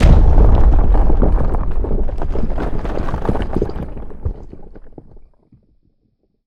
rock_avalanche_landslide_debris_02.wav